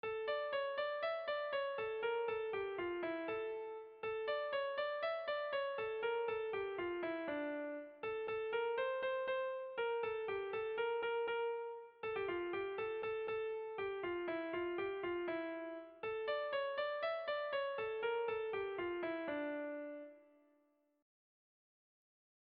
Kontakizunezkoa
Hamarreko txikia (hg) / Bost puntuko txikia (ip)
A-A-B-C-A